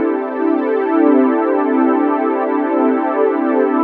cch_synth_lakeside_125_Dm.wav